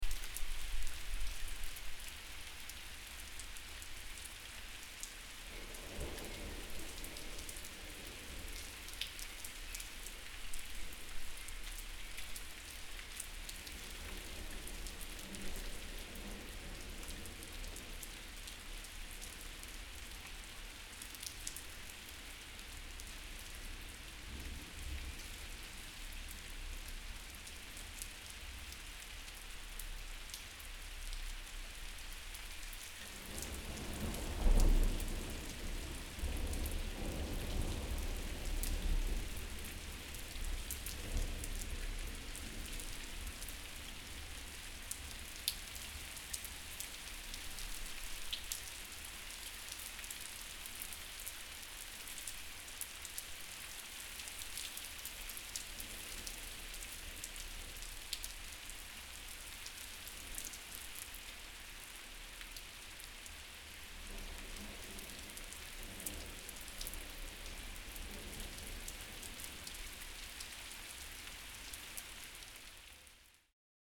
Звуки природы
Дождь с громом